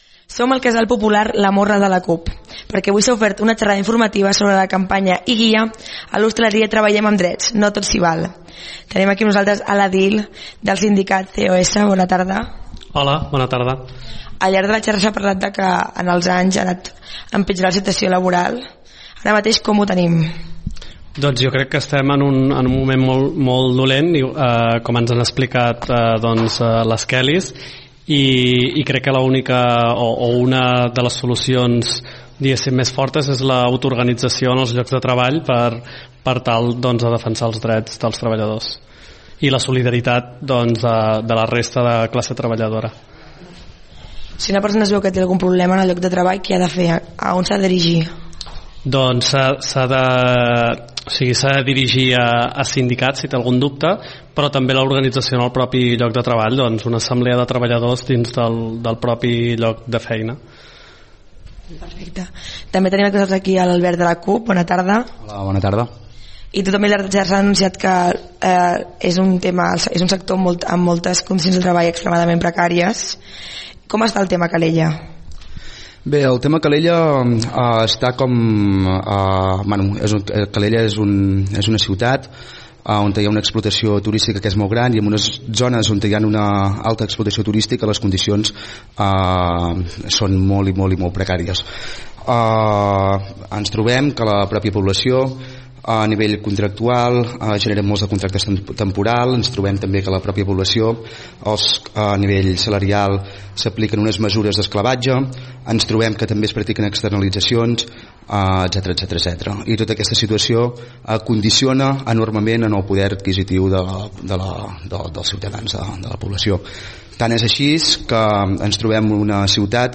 El Casal Popular La Morra va acollir una xerrada informativa sobre els drets a l’hostaleria.